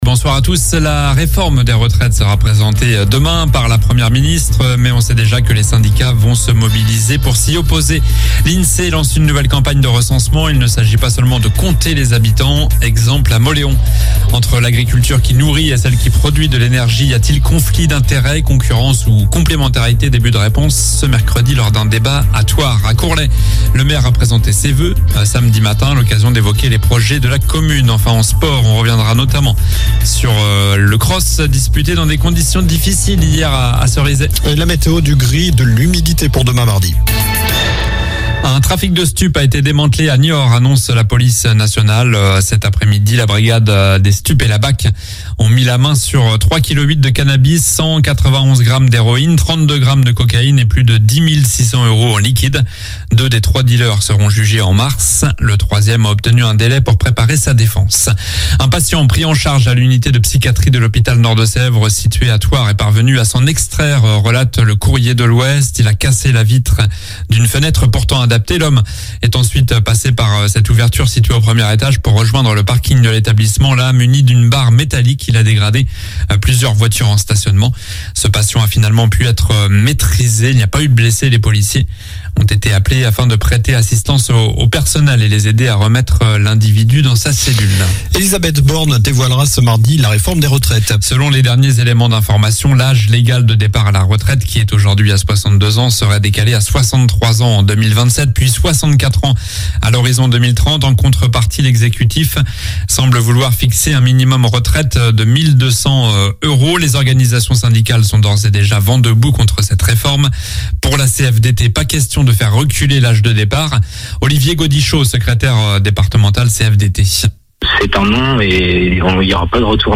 Journal du lundi 09 janvier (soir)